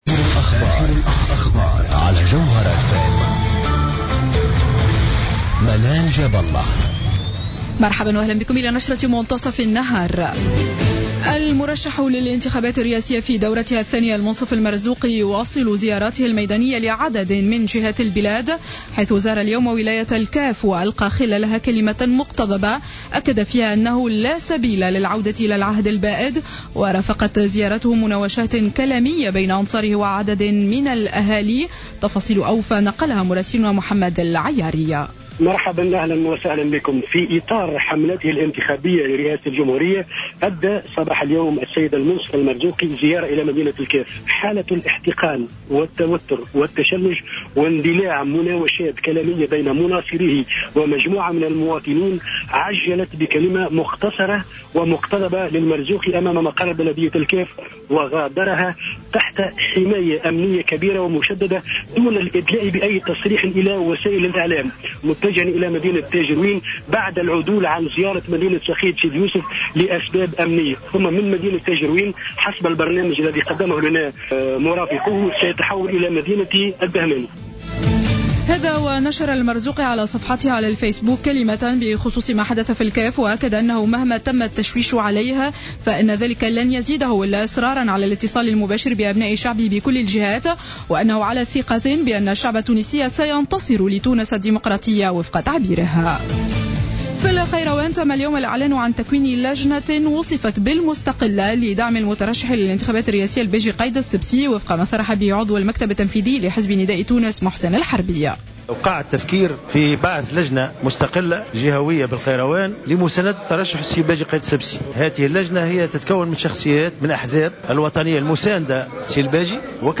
نشرة أخبار منتصف النهار ليوم السبت 13-12-14